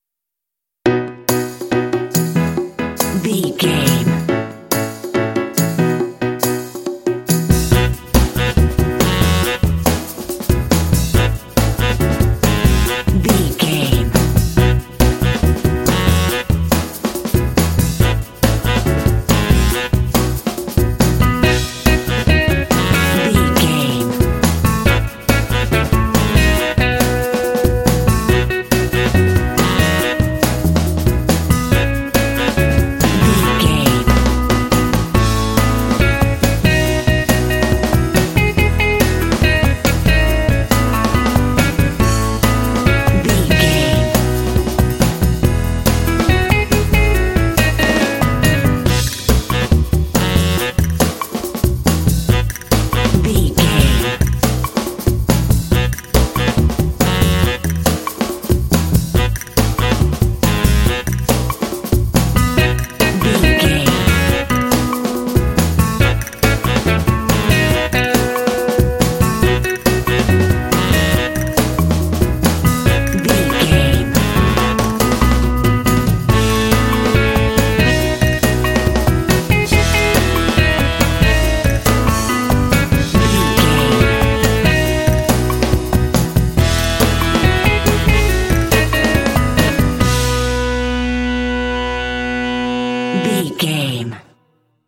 Uplifting
Ionian/Major
bouncy
groovy
piano
drums
saxophone
bass guitar
electric guitar
pop
quirky
kitschy